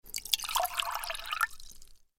دانلود آهنگ آب 8 از افکت صوتی طبیعت و محیط
جلوه های صوتی
دانلود صدای آب 8 از ساعد نیوز با لینک مستقیم و کیفیت بالا